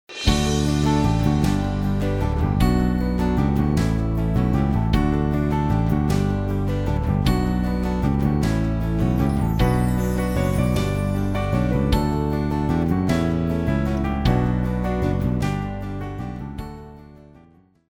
Contemporary 101c